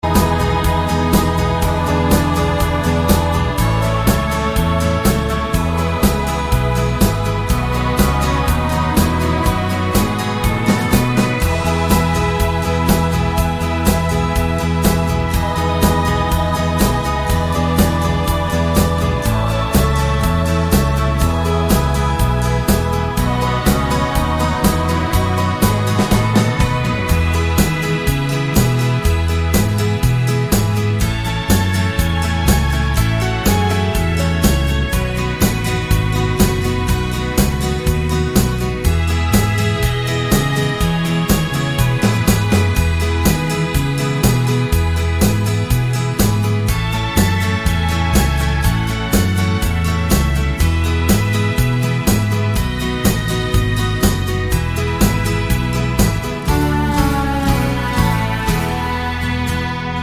Live Version Pop (1970s) 2:59 Buy £1.50